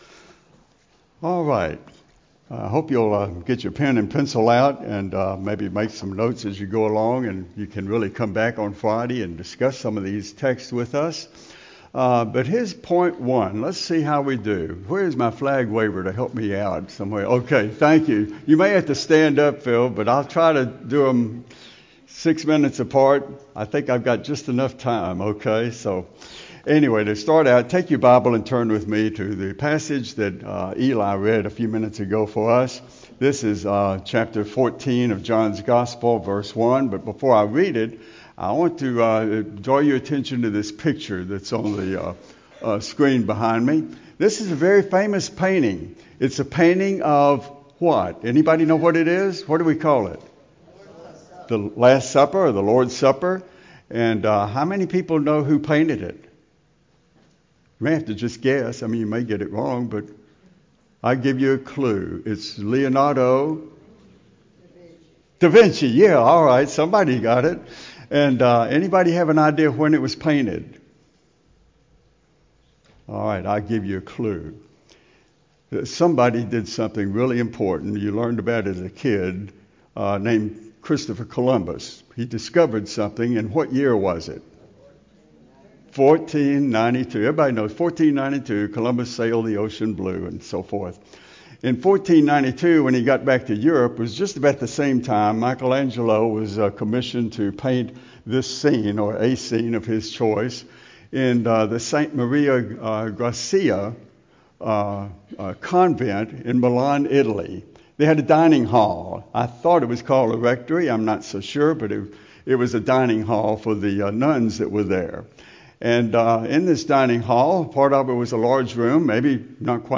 Spiritual Warfare Sunday Morning